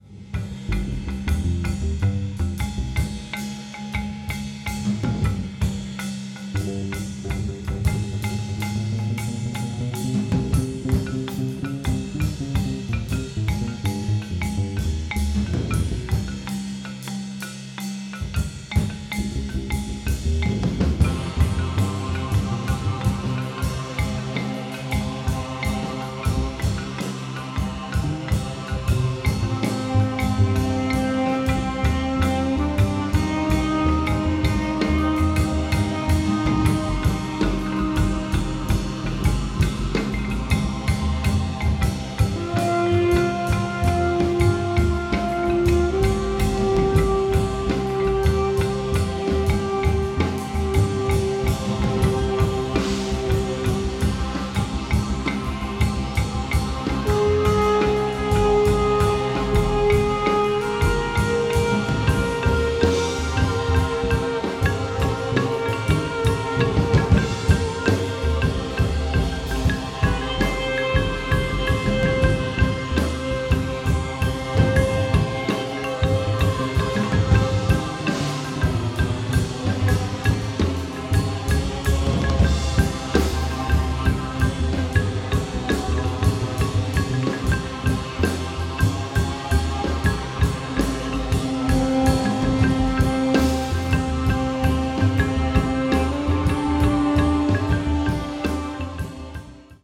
keyboards, synthesizer
drums, synthesizer
pocket, Bb&C trumpets, conch shell, percussion
double bass, electric bass, voice
drums, percussion, marimba, coil spring
at Cloudland Studio, Fort Worth, Texas, USA.